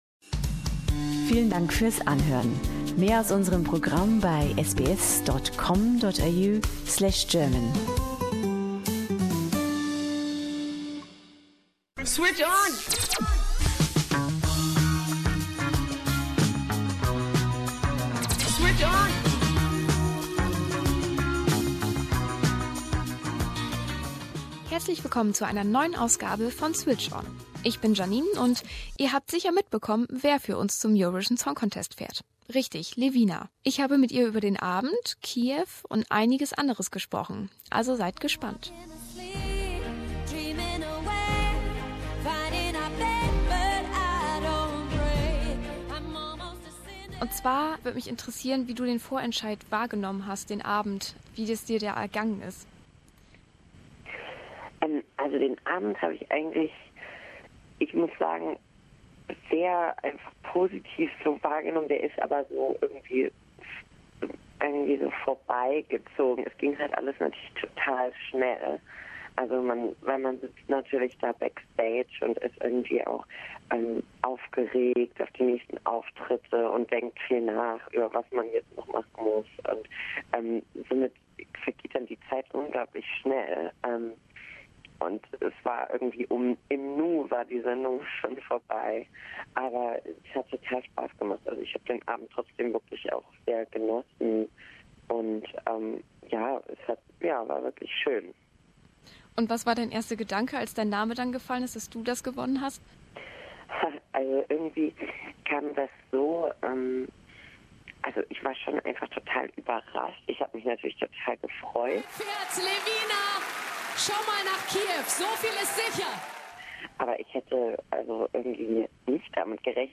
Mit dem Song "Perfect Life" wird Levina Mitte Mai in Kiew die deutschen Fahnen hochhalten. SBS German verrät sie im exklusiven Interview, was sie nach den beiden letzten Plätzen für Deutschland 2015 und 2016 besser machen will und warum sich die 25-Jährige im ersten Moment gar nicht freuen konnte.